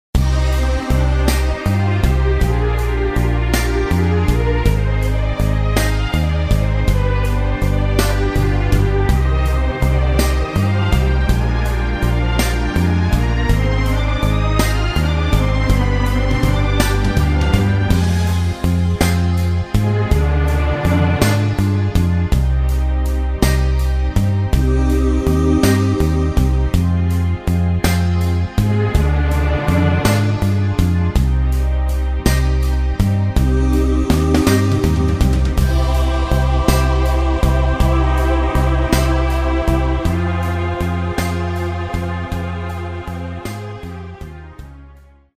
version slow